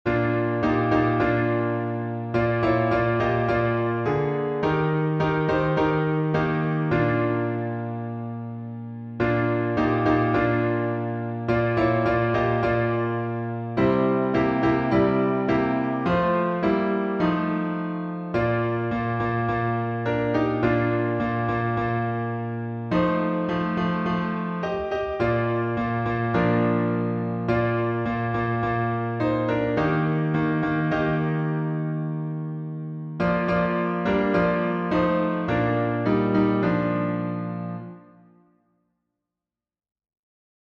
Time signature: 4/4